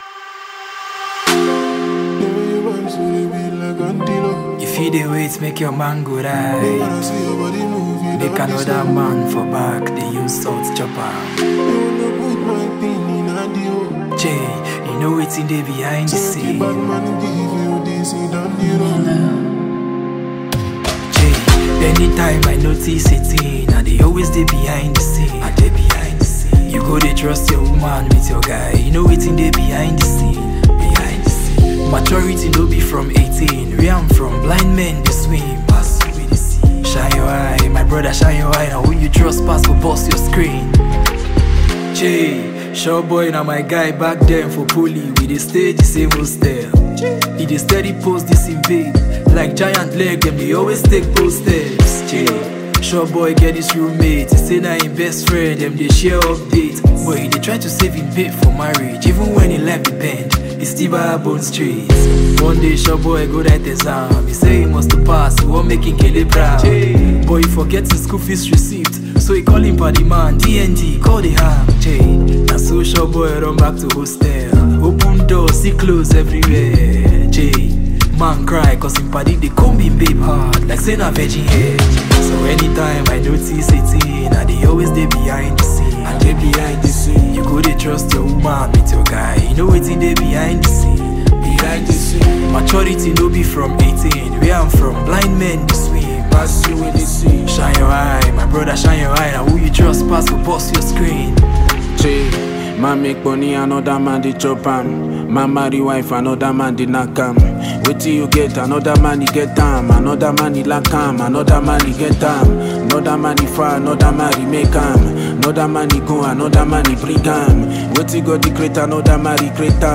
Nigerian talented singer, rapper and songwriter